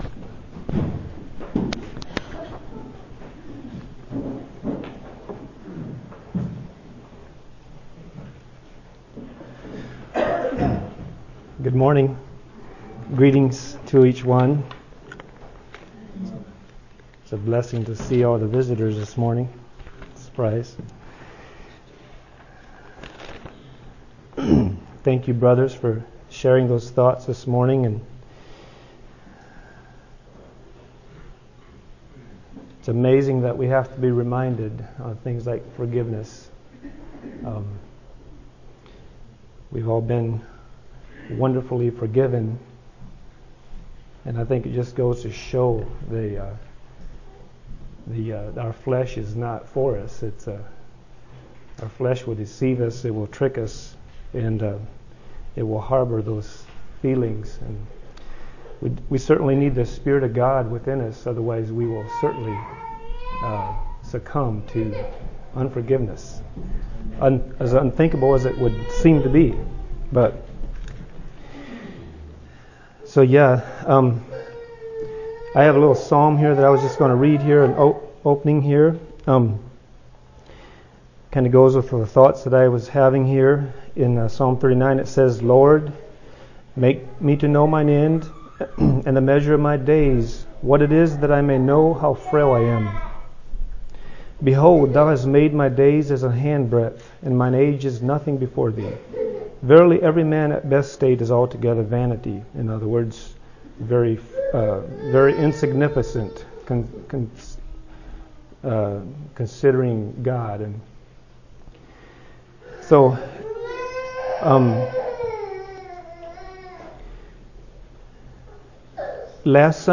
A message from the series "2025 Messages."